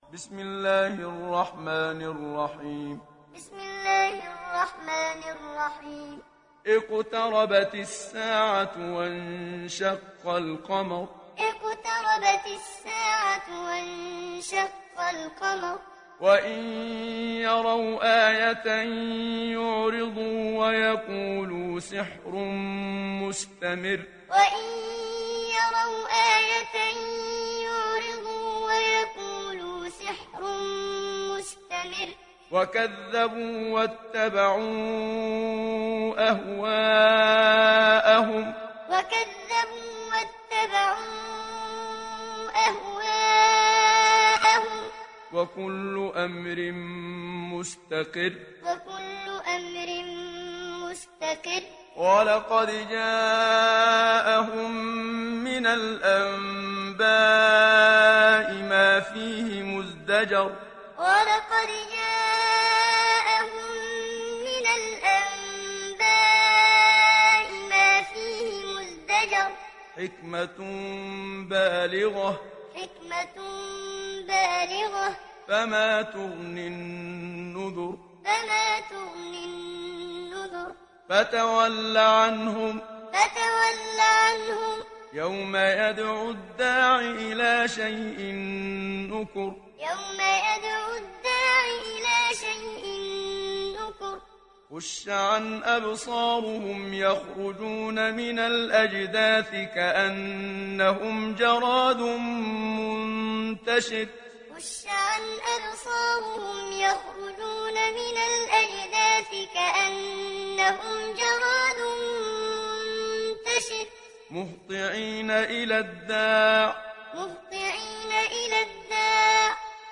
সূরা আল-ক্বামার ডাউনলোড mp3 Muhammad Siddiq Minshawi Muallim উপন্যাস Hafs থেকে Asim, ডাউনলোড করুন এবং কুরআন শুনুন mp3 সম্পূর্ণ সরাসরি লিঙ্ক
ডাউনলোড সূরা আল-ক্বামার Muhammad Siddiq Minshawi Muallim